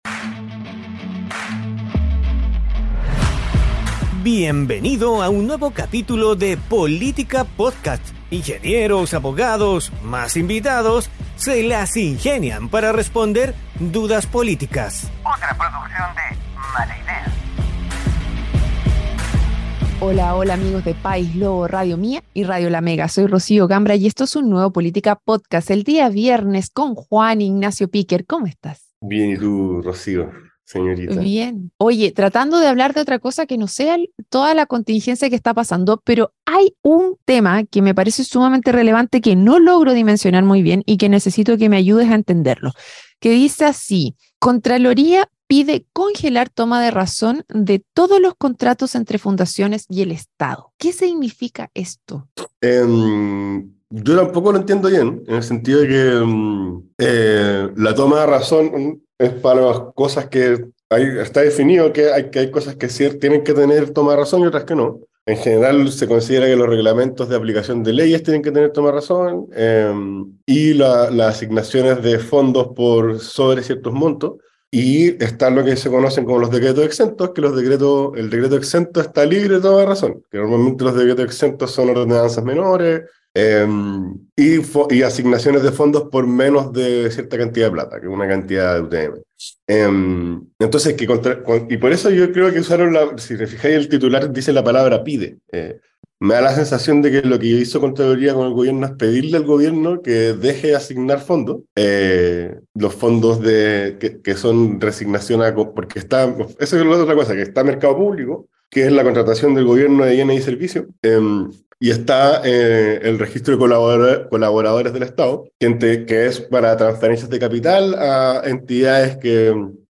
un panel de expertos estables e invitados especiales